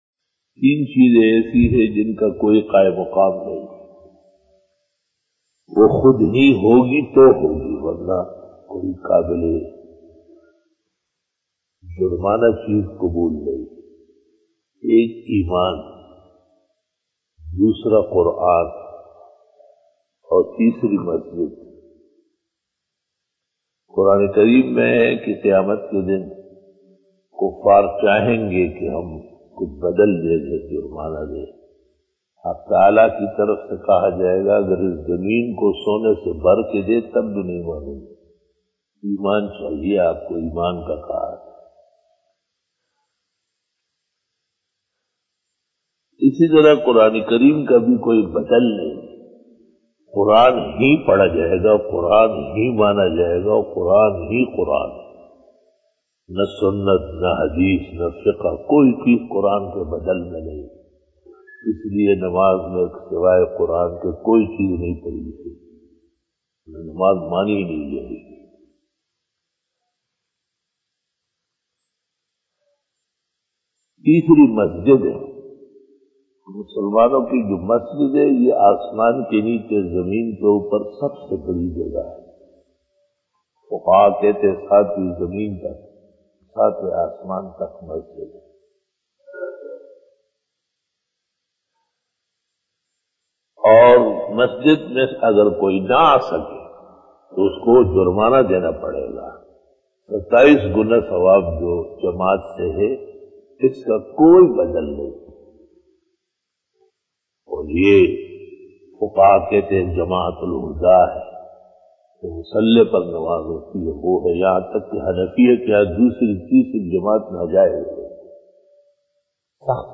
After Fajar Byan
بیان بعد نماز فجر بروز ہفتہ